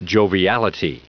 Prononciation du mot joviality en anglais (fichier audio)
Prononciation du mot : joviality